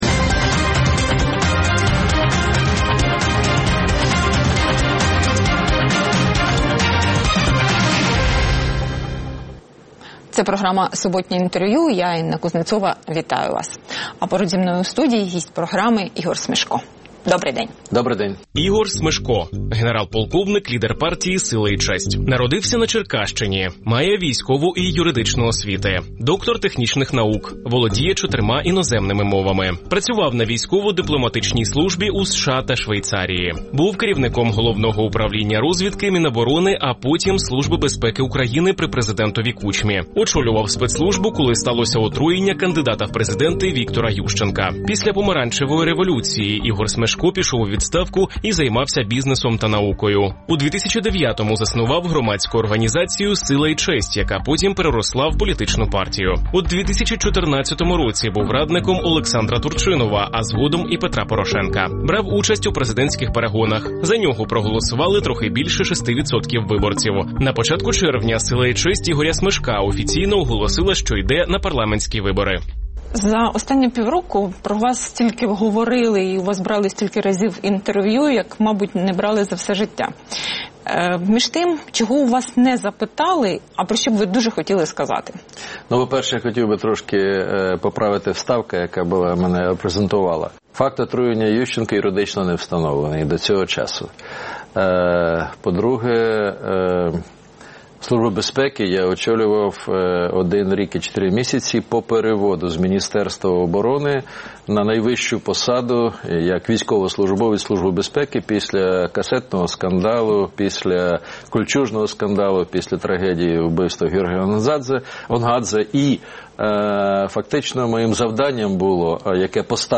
Суботнє інтерв’ю | Ігор Смешко, лідер партії «Сила і честь»
Суботнє інтвер’ю - розмова про актуальні проблеми тижня. Гість відповідає, в першу чергу, на запитання друзів Радіо Свобода у Фейсбуці